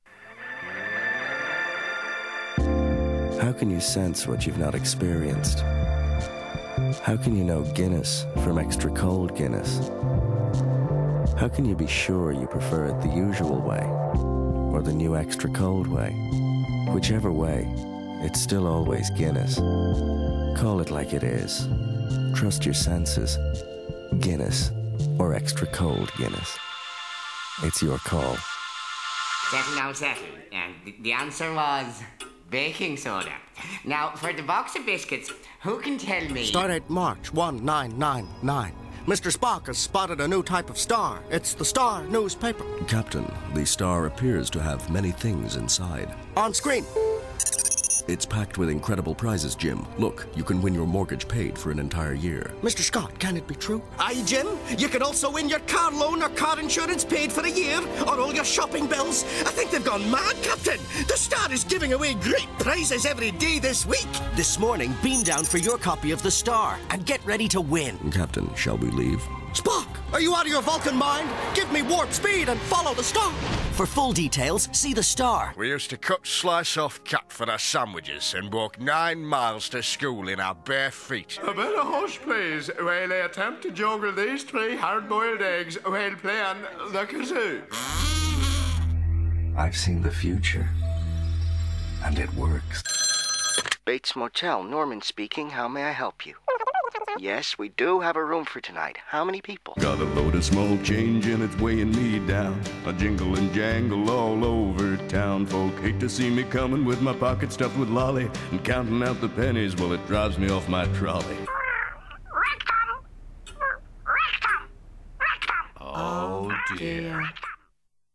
Gaming Voiceover & Animation Voiceover Dublin, Ireland